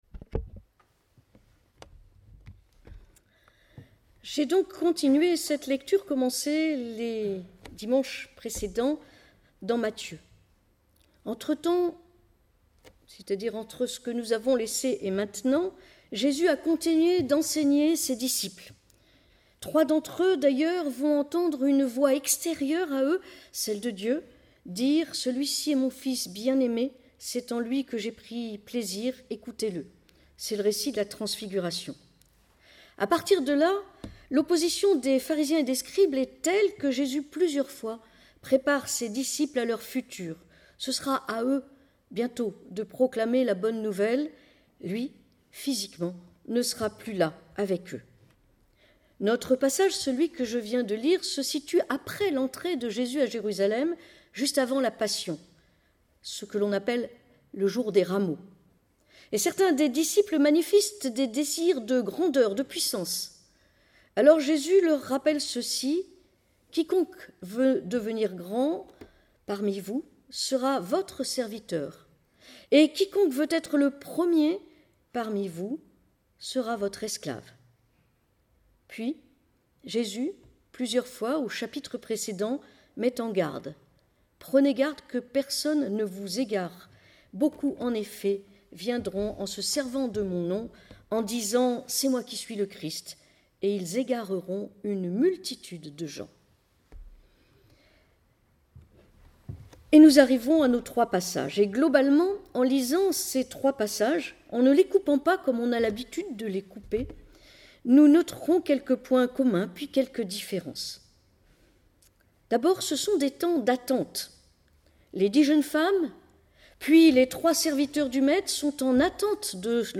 Podcasts prédications